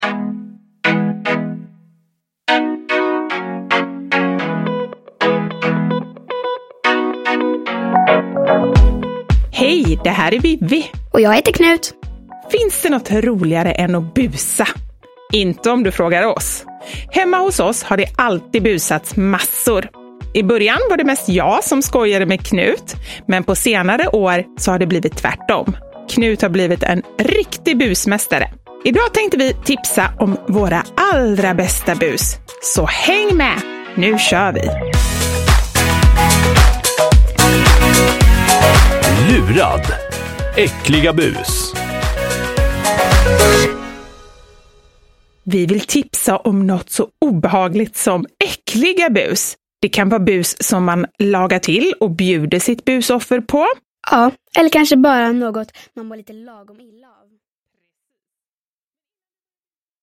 Lurad! Äckliga bus – Ljudbok – Laddas ner